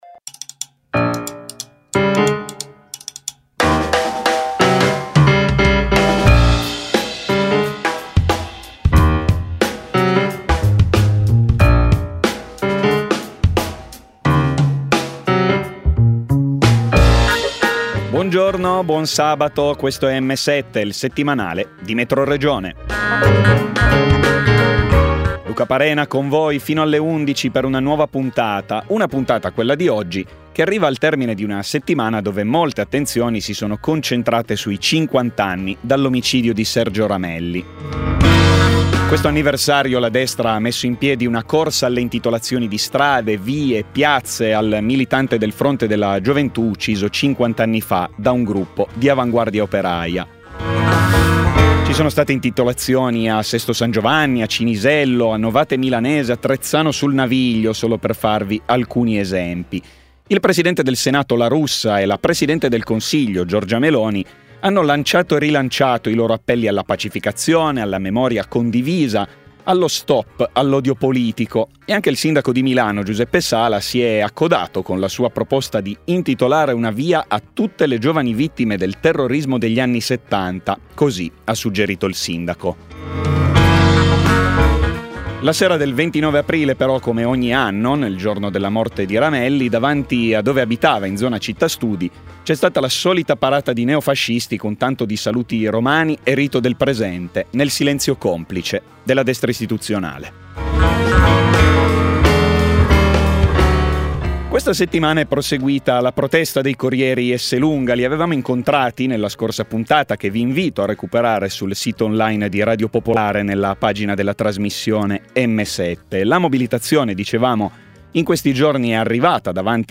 La prima parte delle nostre interviste.